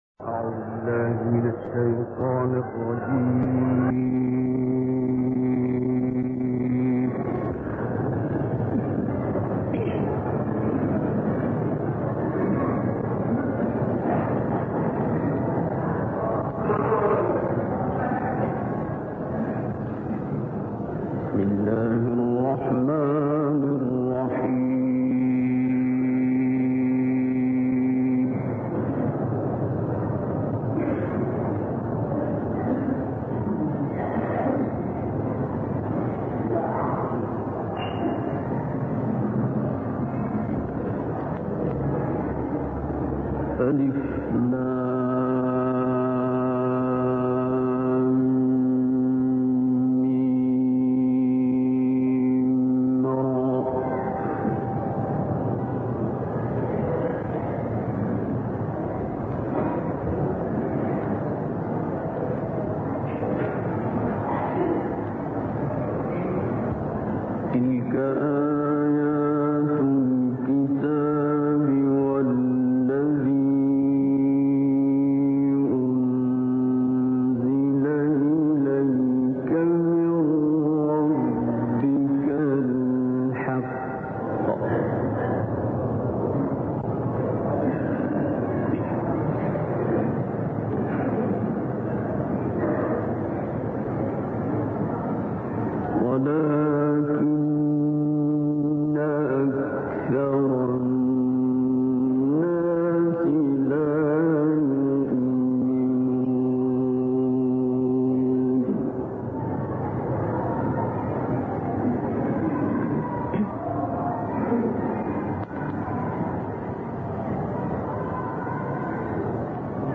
تلاوتی از شنیدنی از عبدالباسط/ آیاتی از سوره مبارکه رعد+صوت
عبدالباسط محمد عبدالصمد قاری نامدار جهان اسلام است که در این گزارش تلاوتی زیبا و کمتر شنیده شده از وی شامل آیات